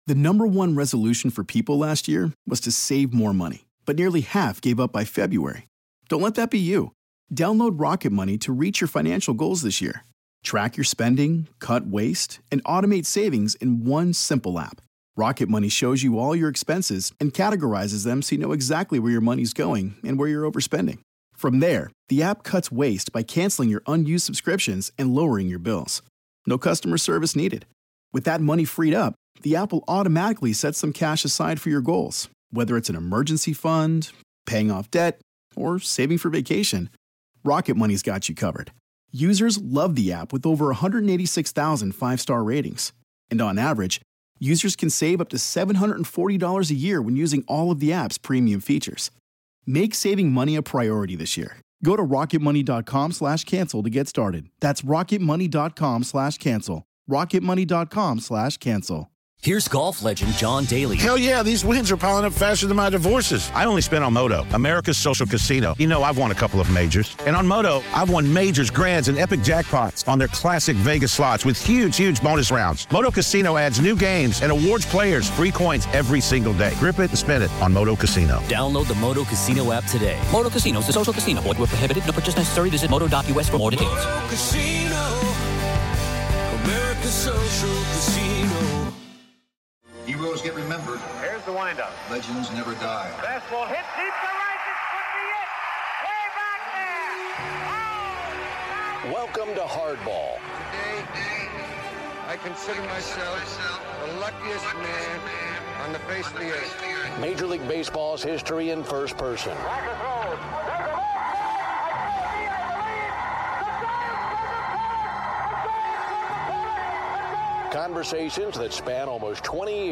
We will do that and so much more with our TWO guests this week. Ed Kranepool & Ron Swoboda. 50+ years later..this team resonates as much an ANY in the history of The Game.